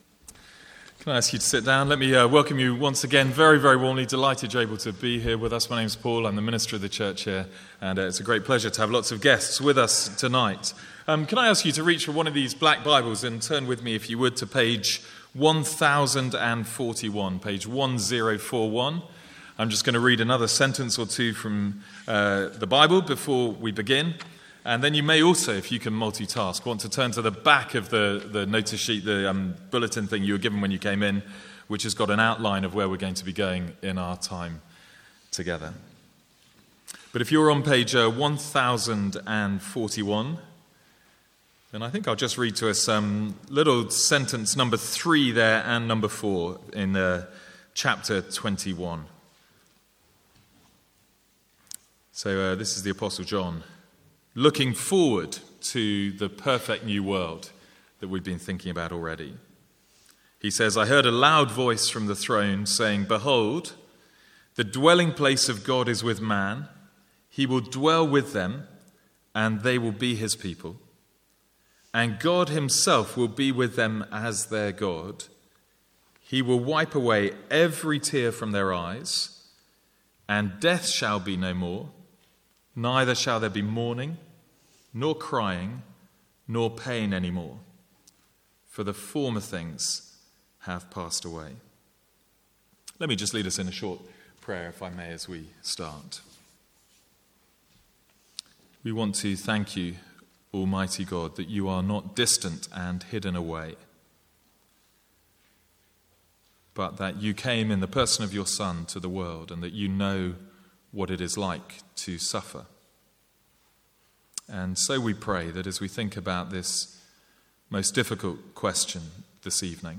Sermons | St Andrews Free Church
From the Sunday evening 'Tough Questions' guest series (with Q&A).